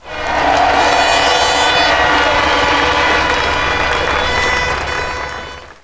crowd_var2.wav